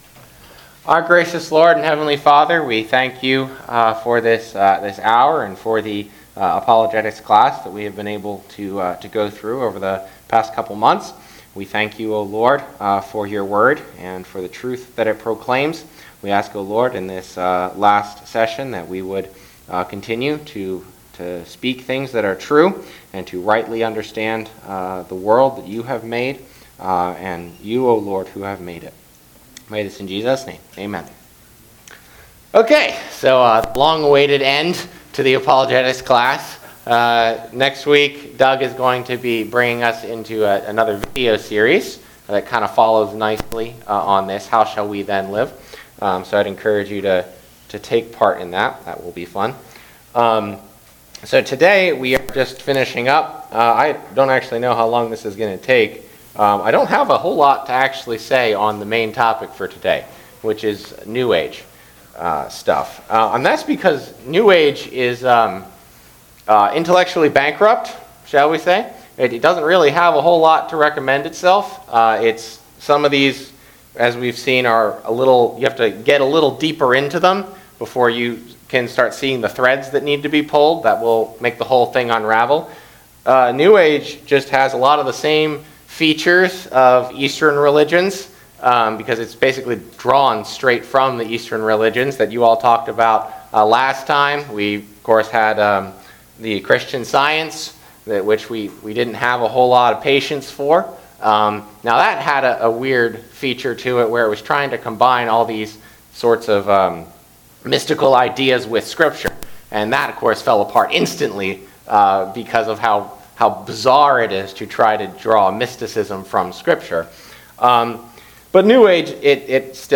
Apologetics Service Type: Sunday School Outline